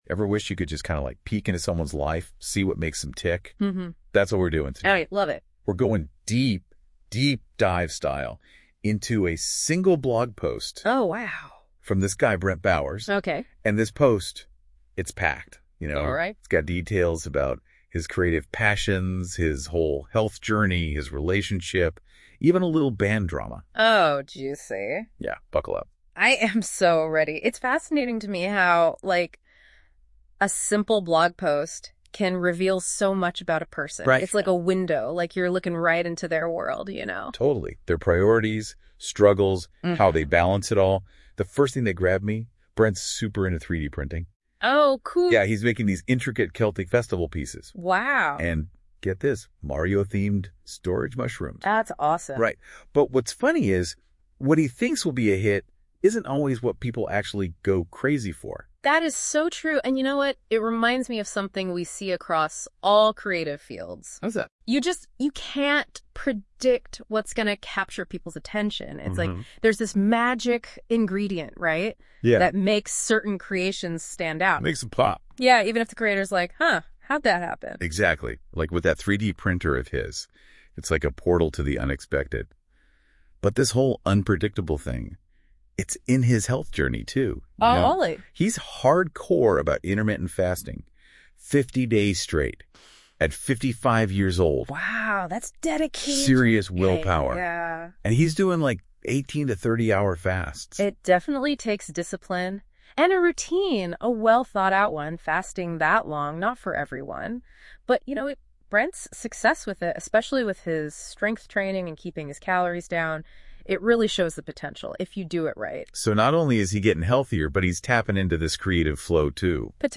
If you’d like to listen to an AI banter about this months activities, you can